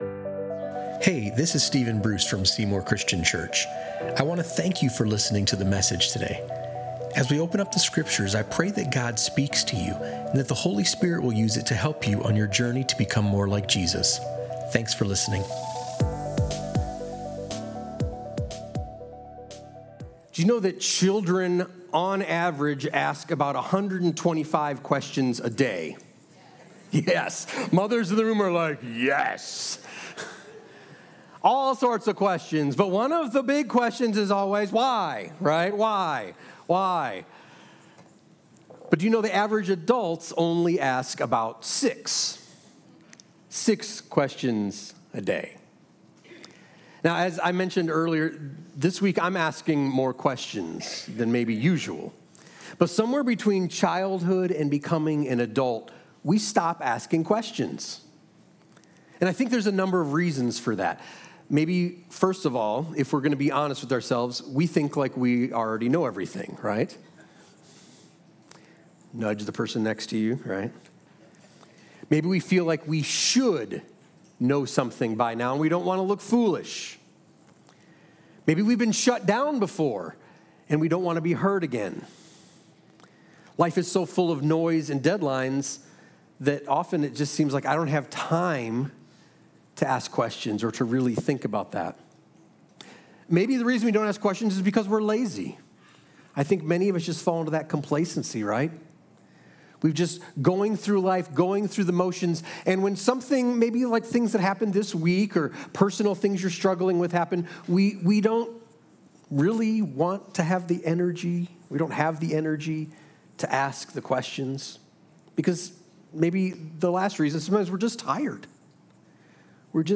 Whether you’re facing unanswered questions, heartbreaking pain, or a struggle to obey, this message offers hope and direction.